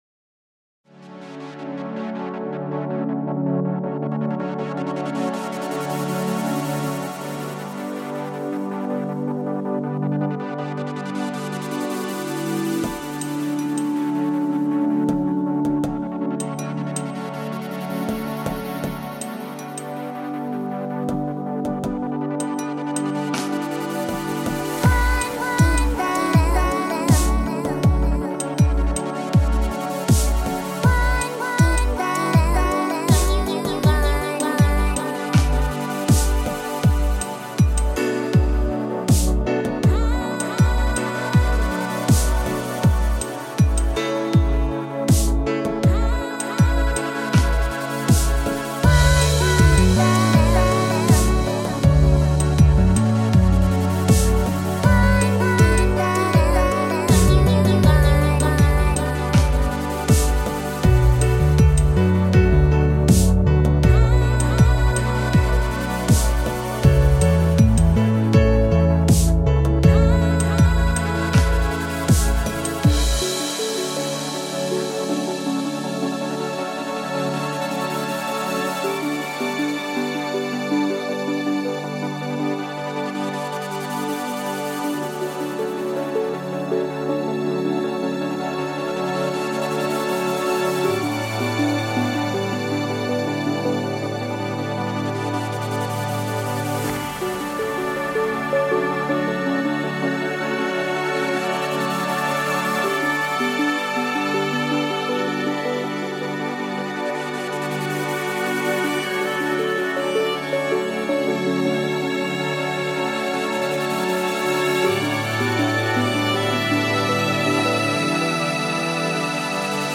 a community "chill" album.